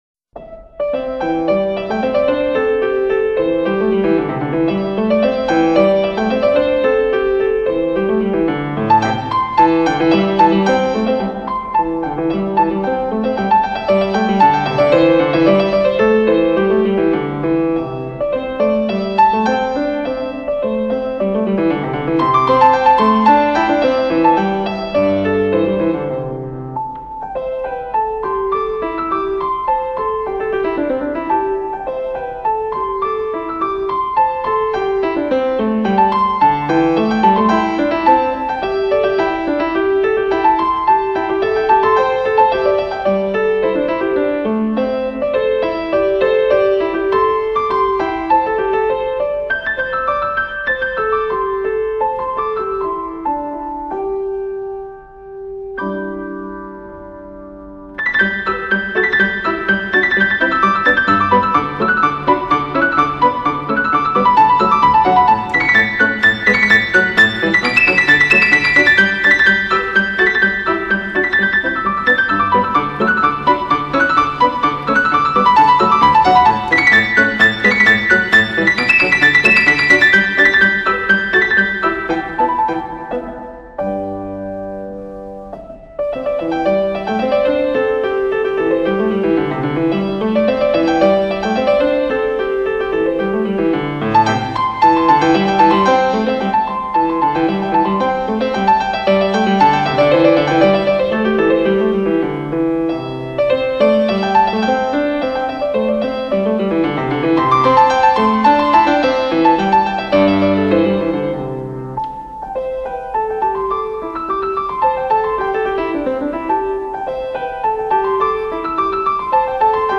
这首乐曲采用民间风味的主题，曲调优美动听，是一首具有浓郁民族风味的钢琴曲。